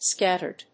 意味・対訳 スキャッチャード